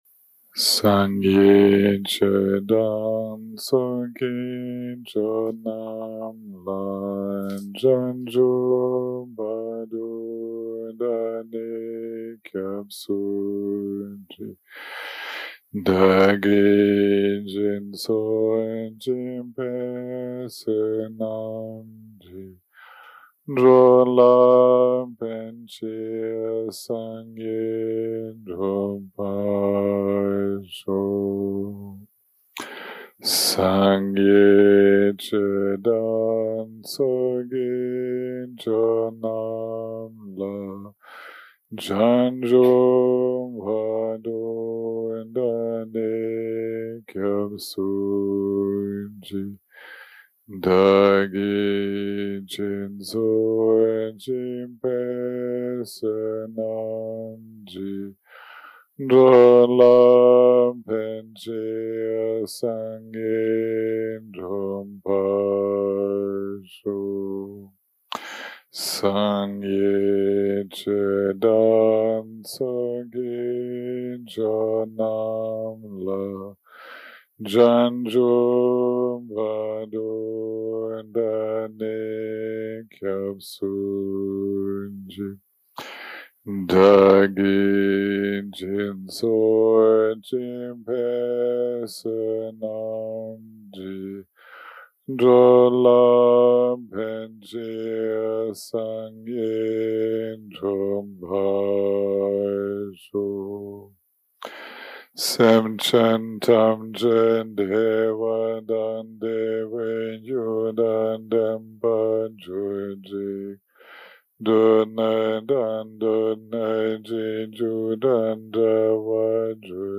day 4 - recording 15 - Afternoon - Discussion + Meditation + Pranayama
day 4 - recording 15 - Afternoon - Discussion + Meditation + Pranayama 0:00 0:00 סוג ההקלטה: Dharma type: Guided meditation שפת ההקלטה: Dharma talk language: English